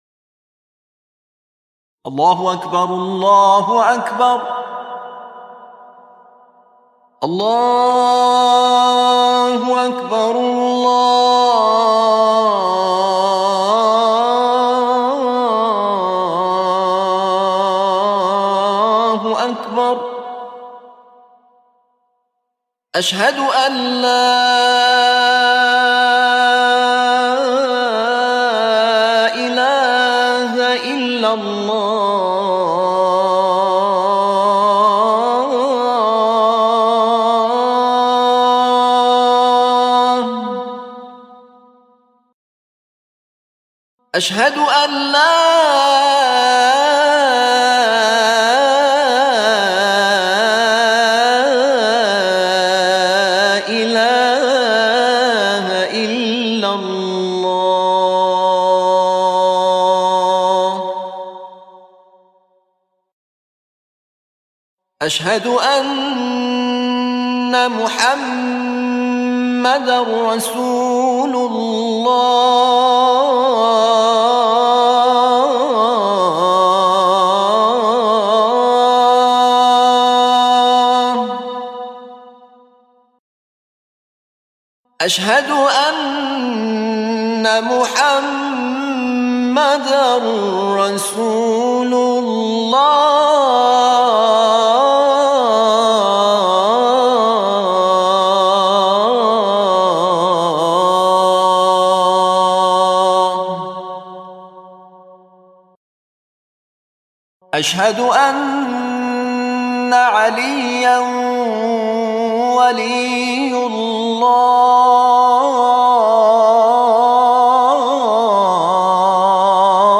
وی ادامه داد: تقریباً شش ماه بود که تم اذان خود را در مایه بیات حسینی کار کرده بودم و هر روز این تم را در مسیر محل کار و خانه در اتومبیل تمرین می‌کردم تا اینکه روزی برای تلاوت در شورای عالی قرآن دعوت شدم و آن روز احساس کردم که صدایم آمادگی گفتن این اذان را دارد و از دوستانی که در استودیو بودند خواستم که این اذان را بگویم و ایشان نیز پذیرفتند و اذان من را ضبط کردند.
اذان